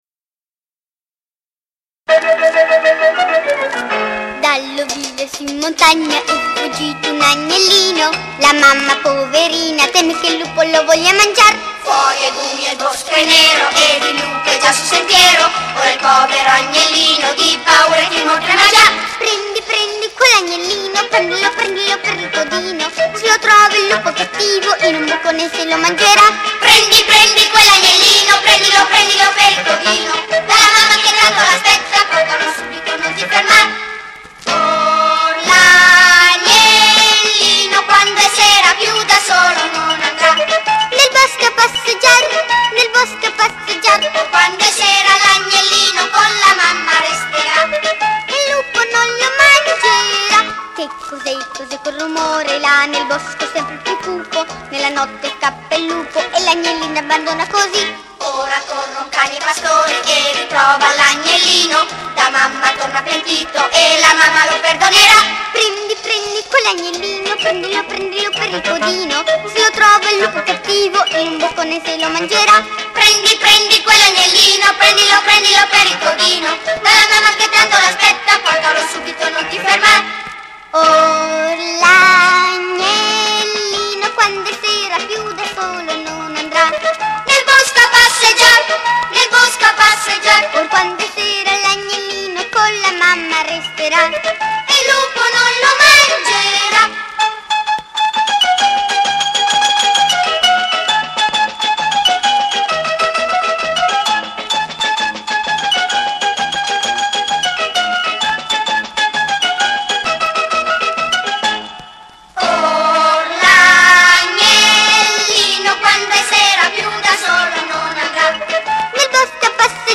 CORO E ORCHESTRA